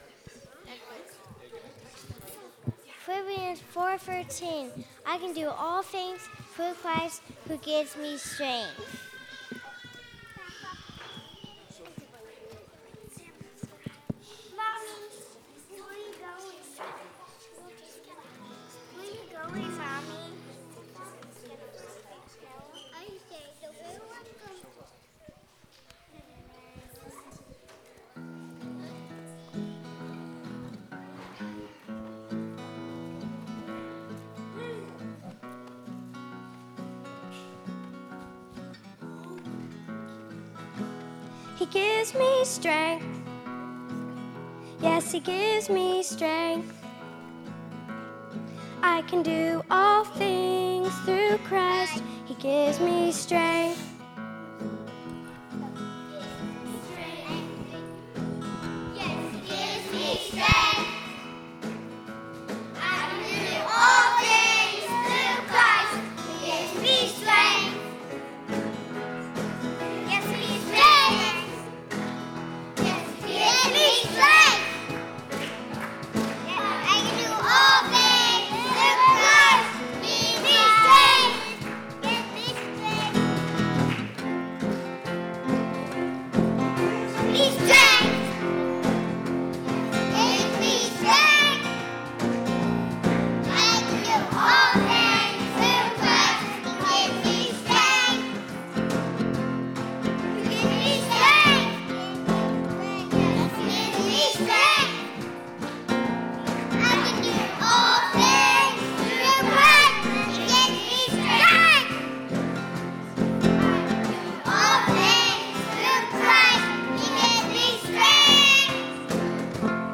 Music Forum • Tiny Tot Choir • He Gives Me Strength